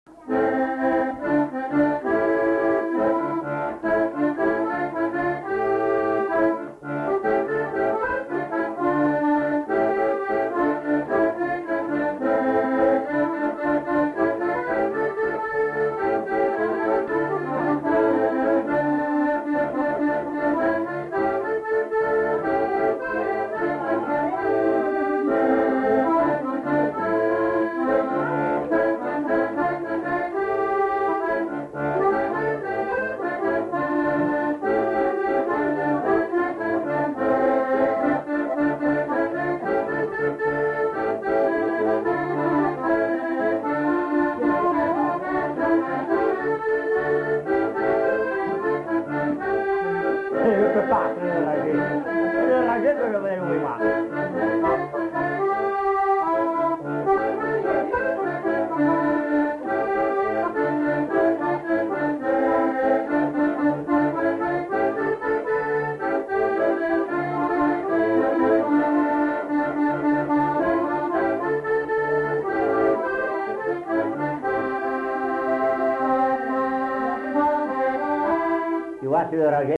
Lieu : Pindères
Genre : morceau instrumental
Instrument de musique : accordéon diatonique
Danse : valse